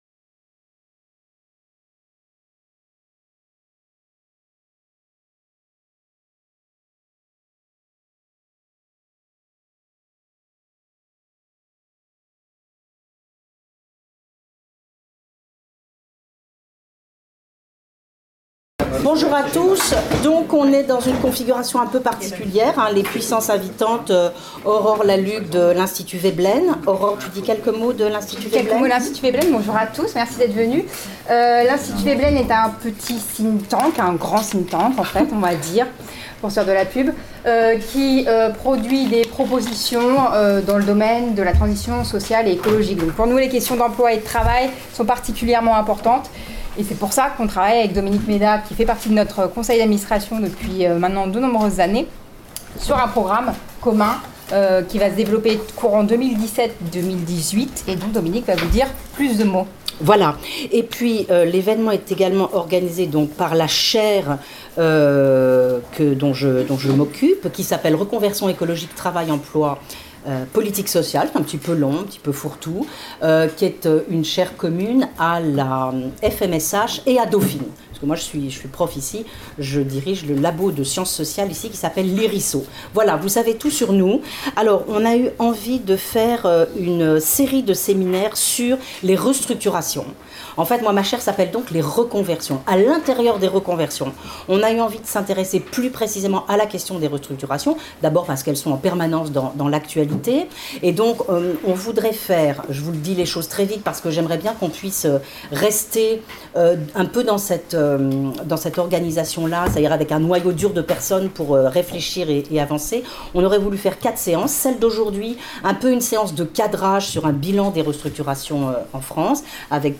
Séminaire animé par Dominique Méda et Aurore Lalucq